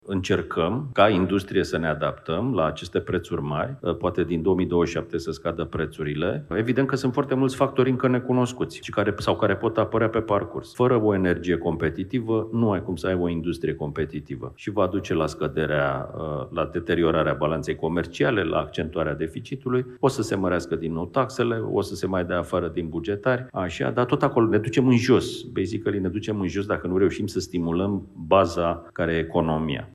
în cadrul forumului „InvestEnergy”